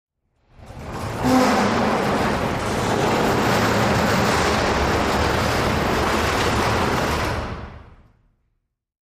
Garage Door
fo_garagedr_lg_close_01_hpx
Large and small garage doors are opened and closed.